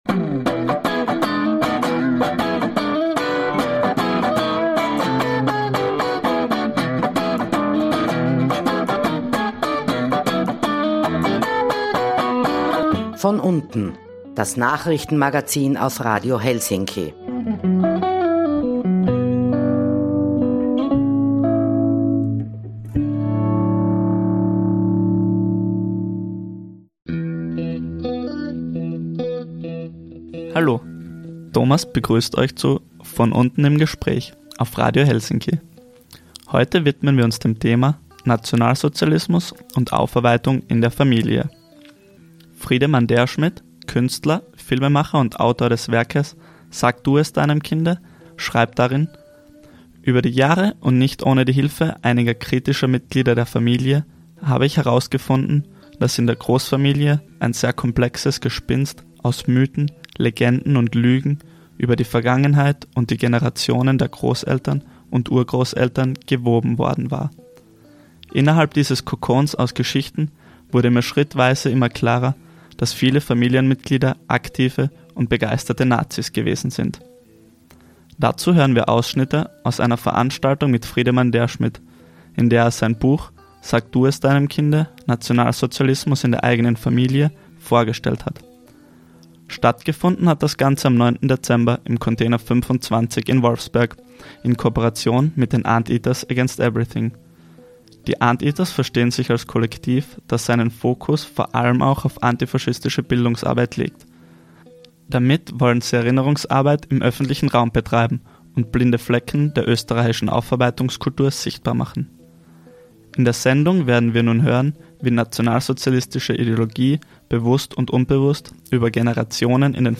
Die Ausschnitte stammen aus einem Vortrag, der am 9.Dezember 2015 im Container 25 in Hattendorf/Wolfsberg stattgefunden hat.